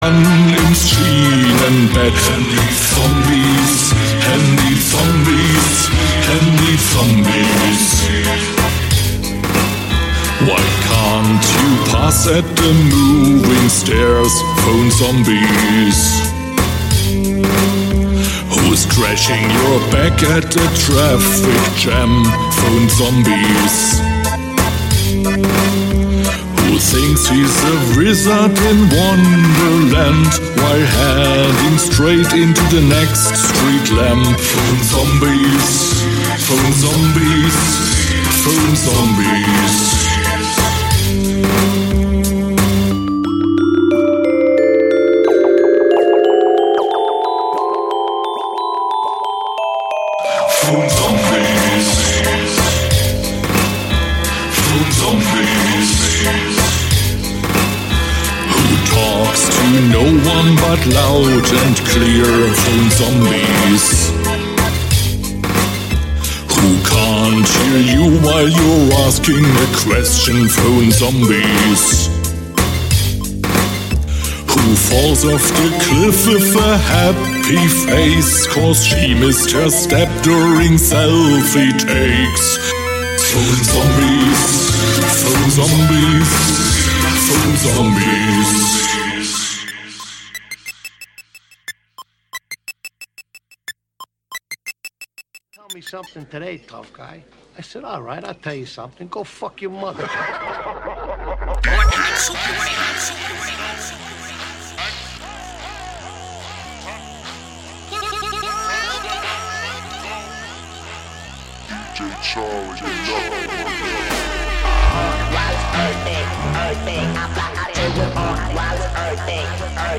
Live from Pacesetters Kitchen Radio (Malaga, Spain)
Live from Pacesetters Kitchen Radio (Malaga, Spain): Basspistol Radio (Audio) Nov 12, 2025 shows Live from Pacesetters Kitchen Radio (Malaga, Spain) Basspistol Radio Station! 777% without commercials! RobotDJ-sets and live interventions!